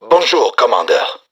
[[Category:幻影坦克(红色警戒3)语音]]
AUMirag_VoiSeld.wav